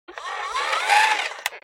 جلوه های صوتی
دانلود صدای ربات 40 از ساعد نیوز با لینک مستقیم و کیفیت بالا